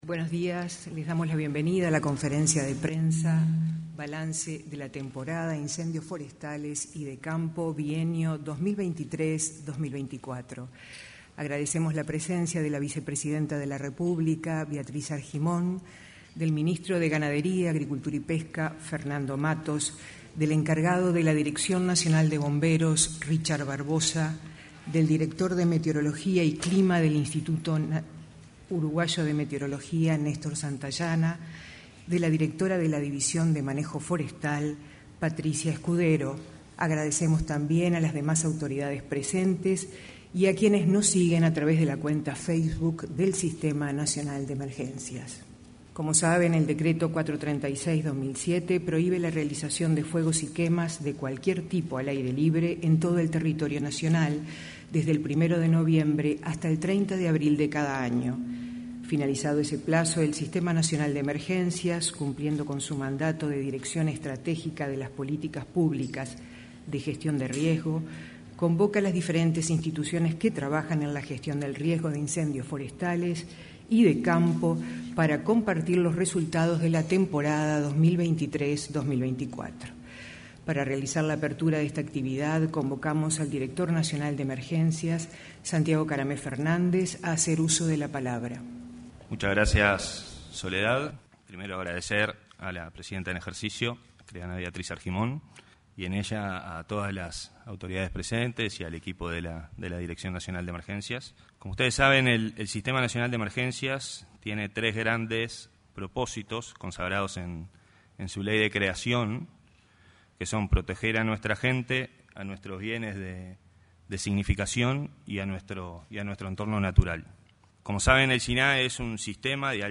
Este lunes 6, en el auditorio de la Torre Ejecutiva anexa, el director del Sistema Nacional de Emergencias, Santiago Caramés; el titular del